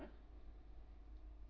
room_dishwasher-56-12.wav